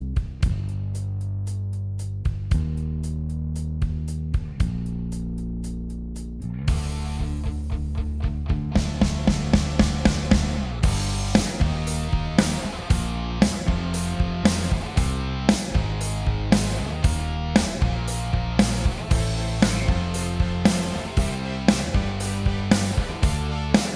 Minus Lead And Solo Guitar Pop (1970s) 4:34 Buy £1.50